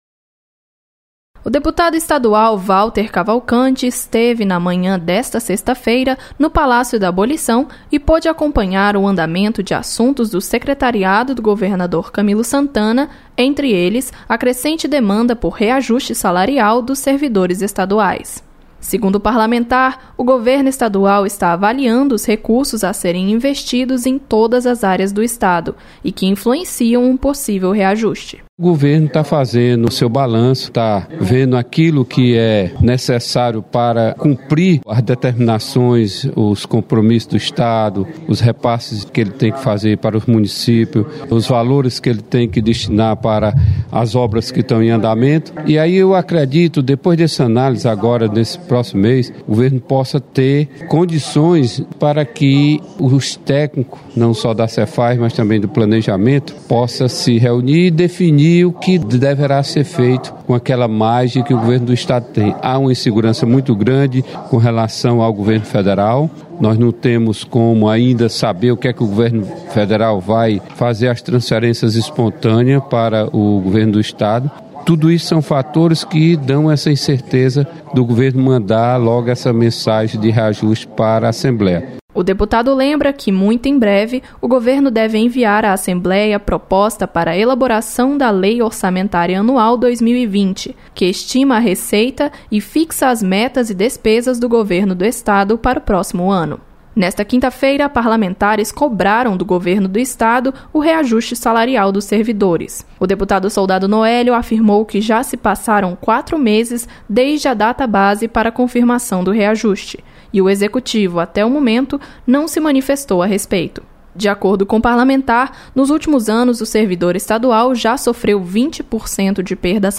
Deputado comenta andamento de reajuste salarial para servidores estaduais. (2'18")